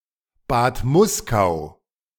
Bad Muskau (German pronunciation: [ˌbaːt ˈmʊskaʊ]
De-Bad_Muskau.ogg.mp3